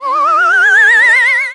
sniper_trail_01.wav